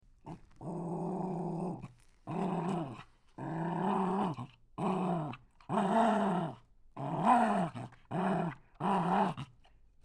Dog Growling 02
Dog_growling_02.mp3